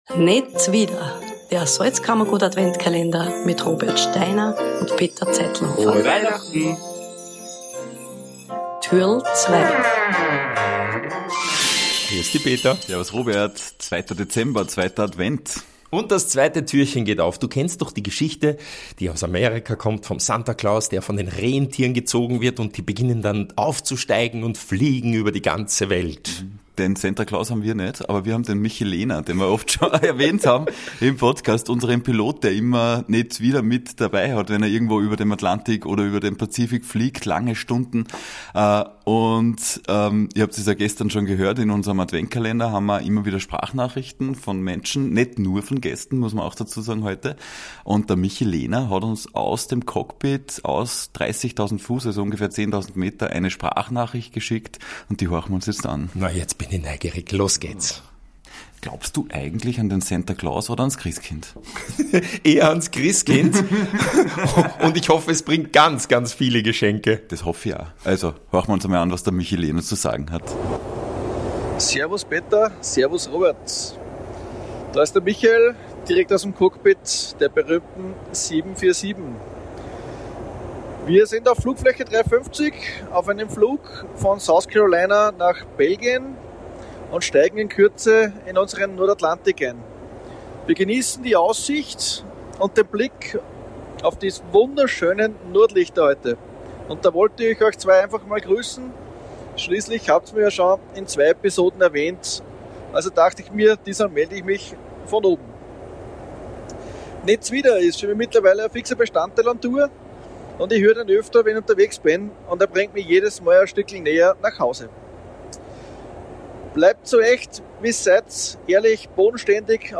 er uns eine Sprachnachricht aus über 10.000 Metern über dem Meer